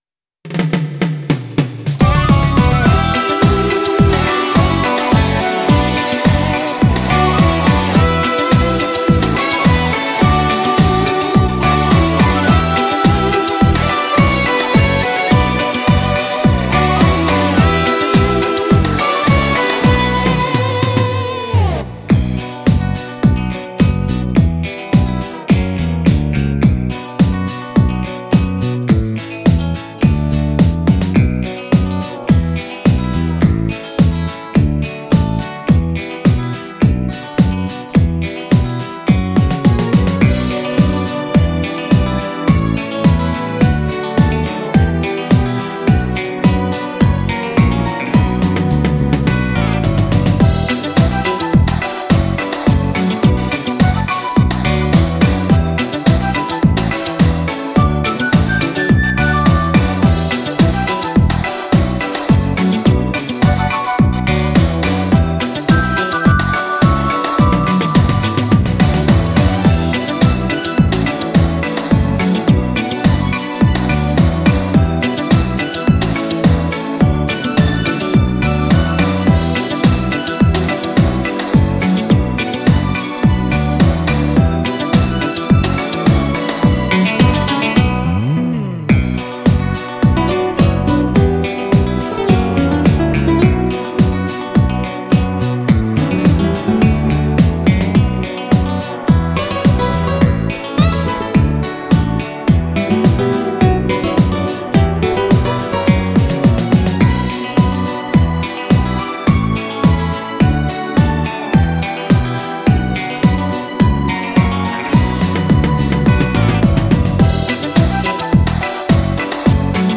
H - moll